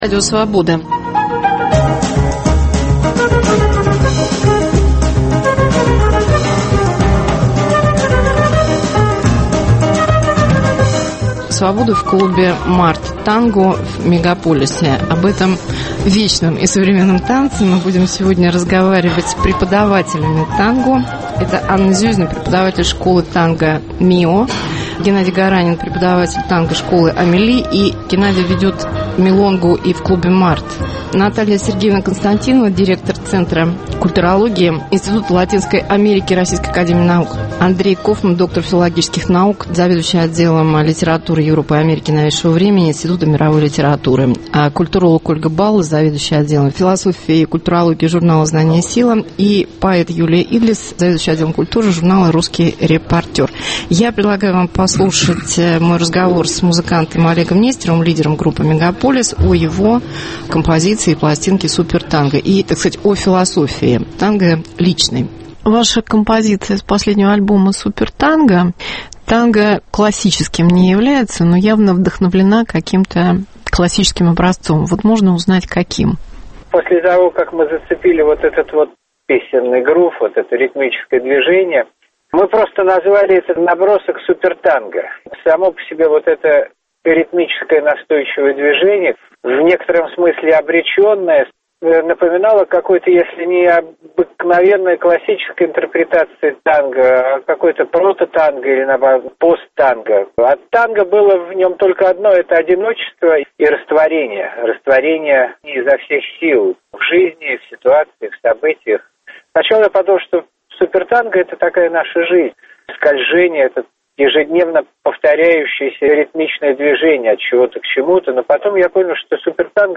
Свобода в клубе "Март". Танго в мегаполисе. Как танец, родившийся в публичном доме, стал объектом Всемирного наследия ЮНЕСКО?